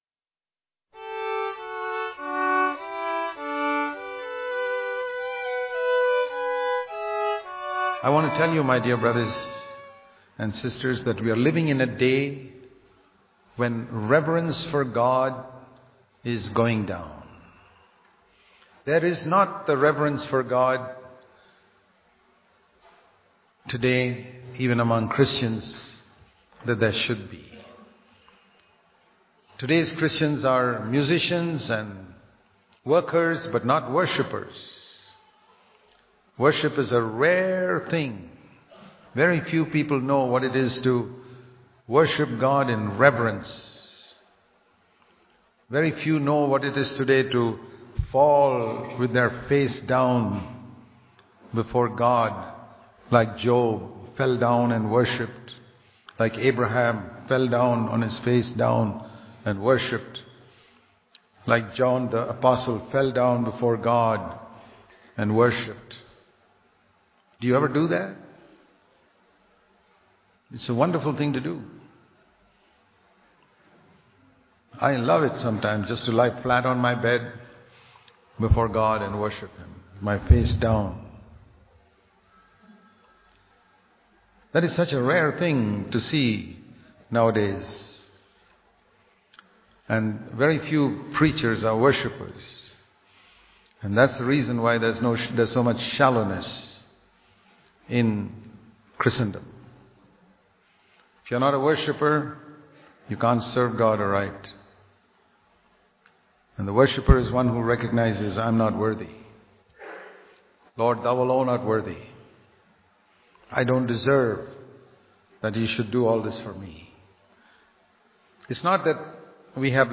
Daily Devotion | A Worshiper Is One Who Has Humility And Reverence Toward God Daily Devotion Speaker